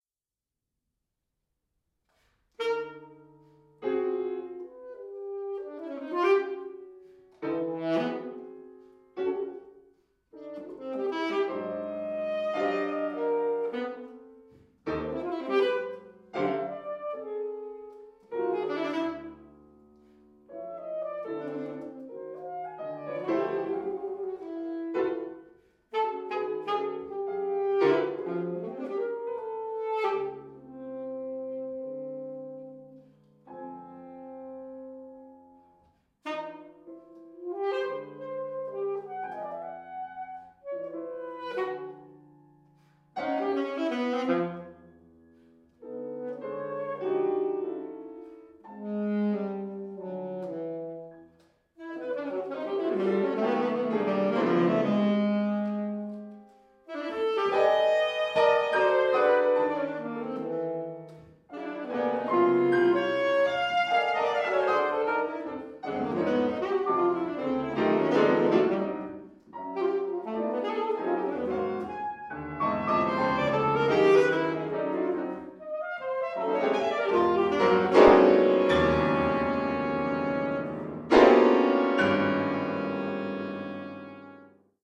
alto saxophone
piano